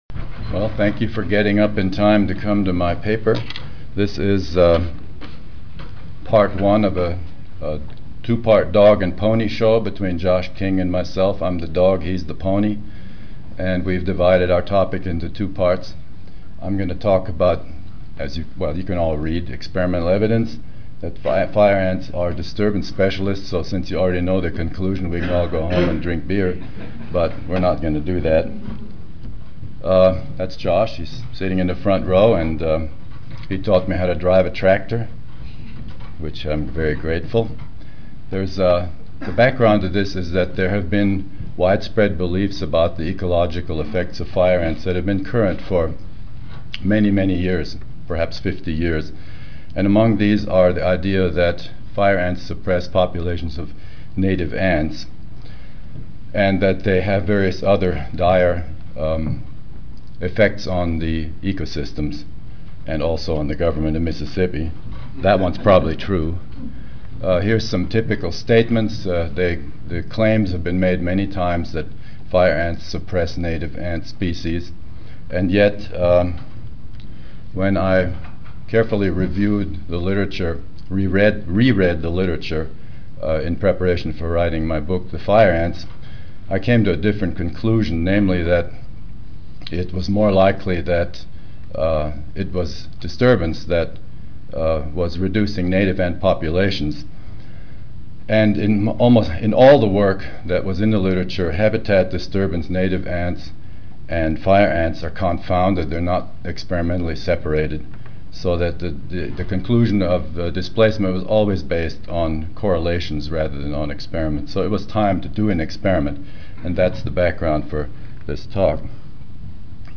Room A8, First Floor (Reno-Sparks Convention Center)
Ten Minute Paper (TMP) Oral